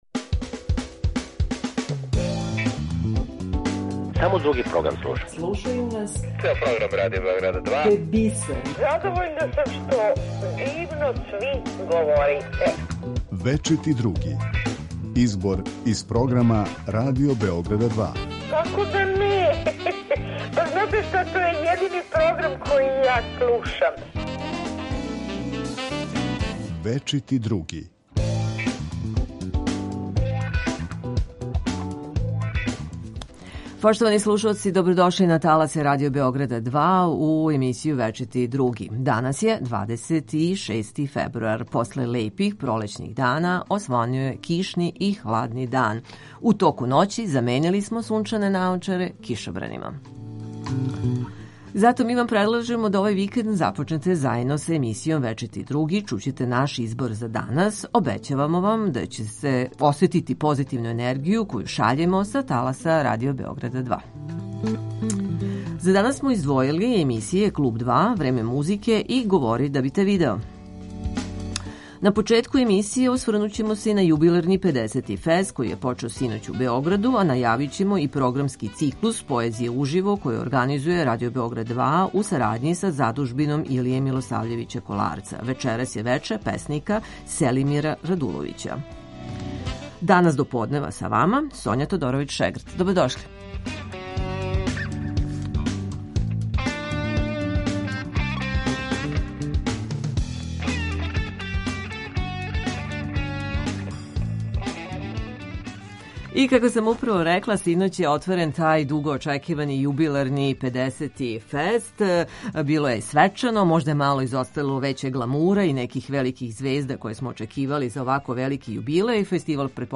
У емисији Вечити Други чућете избор из програма Радио Београда 2